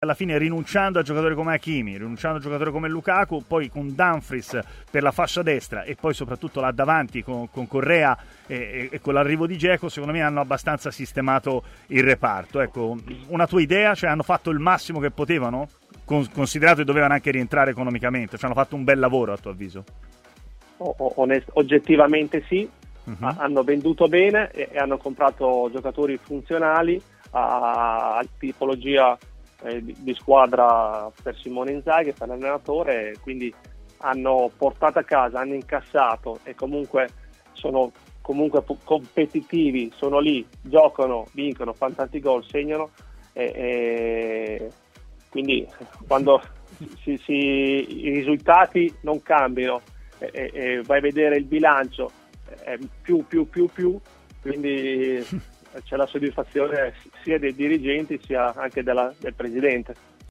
Lunga chiacchierata ai microfoni di TMW Radio per Cristian Zaccardo, che nel corso del suo intervento ha avuto modo di esprimere un giudizio sul mercato condotto in estate da Marotta e Ausilio: "Hanno venduto bene e hanno comprato giocatori funzionali per la tipologia di squadra di Simone Inzaghi.